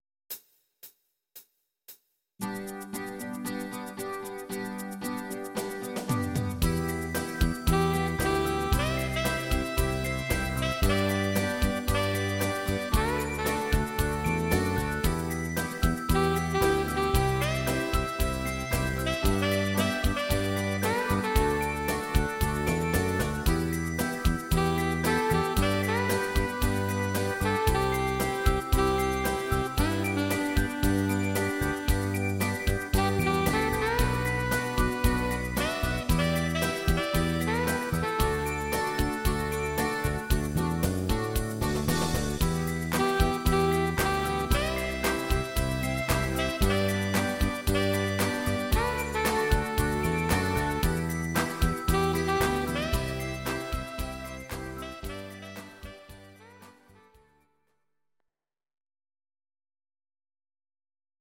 Audio Recordings based on Midi-files
Pop, Oldies, 1960s